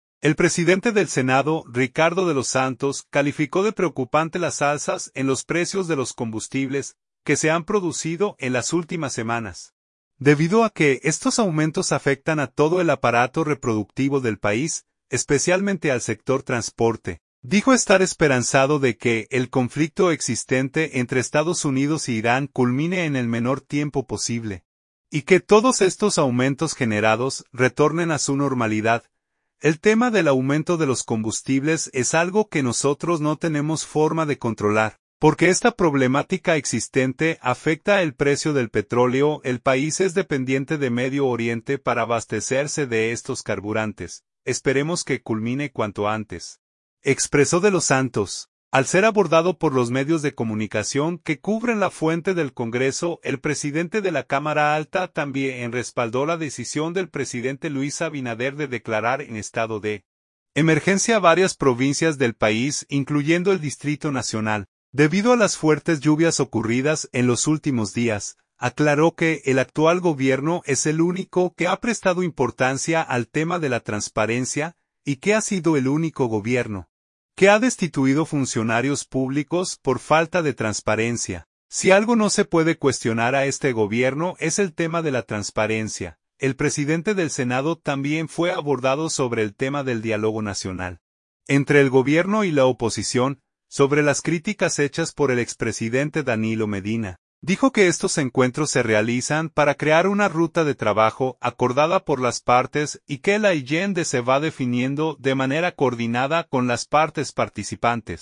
Al ser abordado por los medios de comunicación que cubren la fuente del Congreso el presidente de la Cámara Alta también respaldó la decisión del presidente Luis Abinader de declarar en estado de emergencia varias provincias del país, incluyendo el Distrito Nacional, debido a las fuertes lluvias ocurridas en los últimos días.